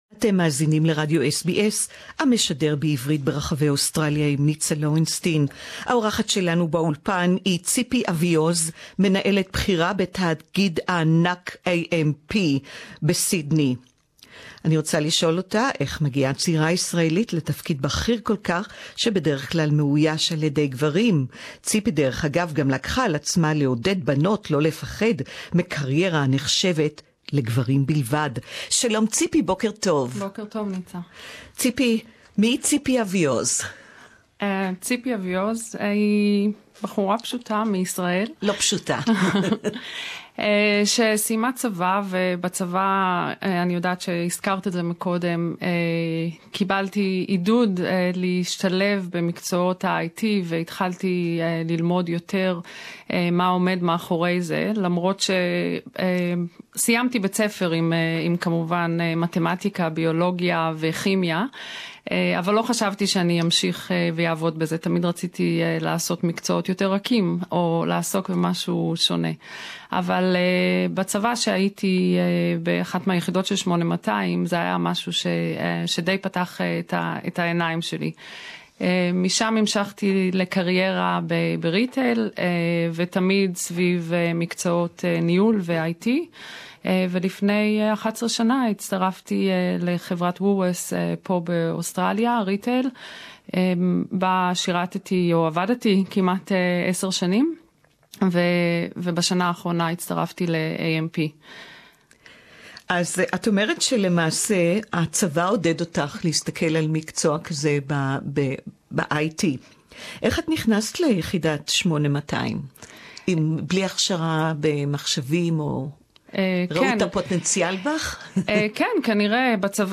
(interview in Hebrew)